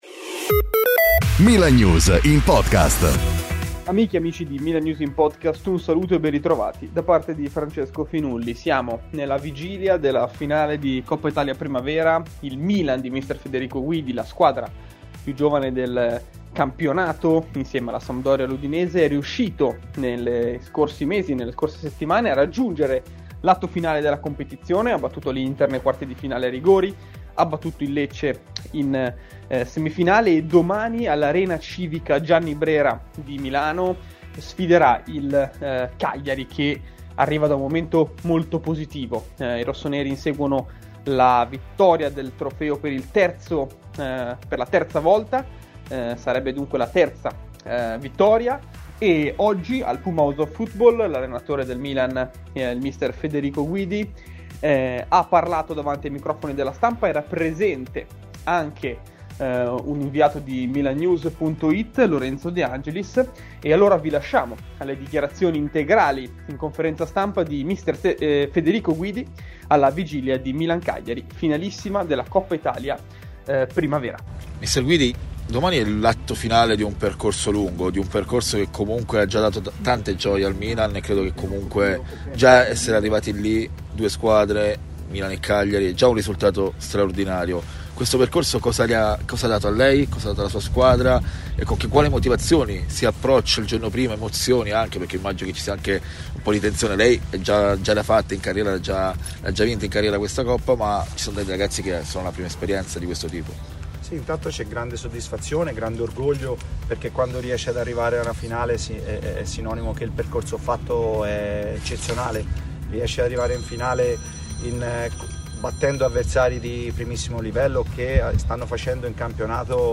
conferenza stampa